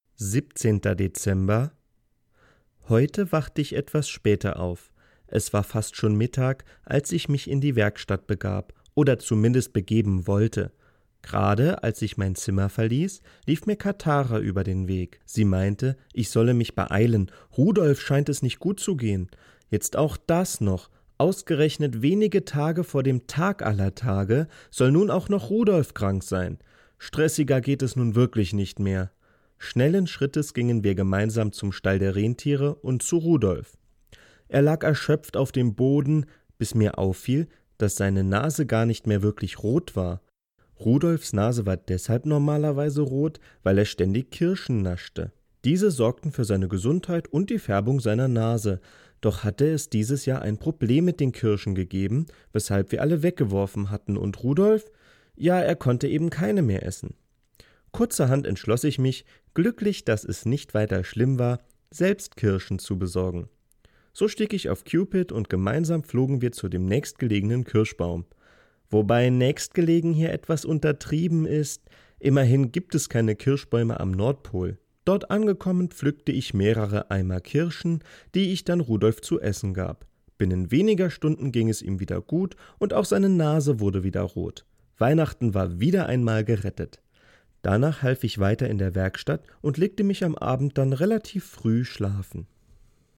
Sprecher*in